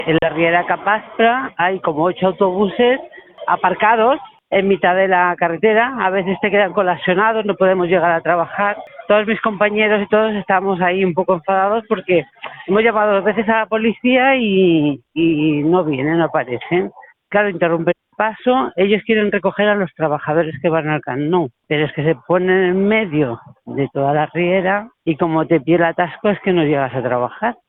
Aquest és el seu testimoni: